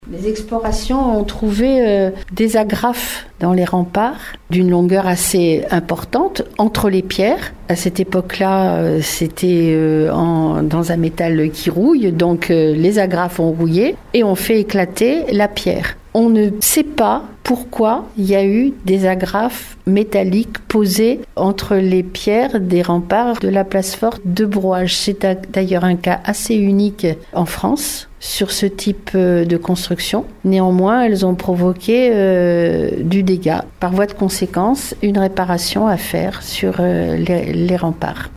Car il en va de la sauvegarde de ce qui est l’un des Plus beaux villages de France, mais qui est aujourd’hui menacé comme nous l’explique la maire Claude Balloteau :